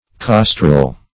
Costrel \Cos"trel\ (k?s"tr?l), n. [CF. W. costrel, OF. costrel,